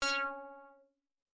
Roland JX3 P Rave Stabbing " Roland JX3 P Rave Stabbing C6（0 Z6ZW
标签： CSharp6 MIDI音符-85 罗兰-JX-3P 合成器 单票据 多重采样
声道立体声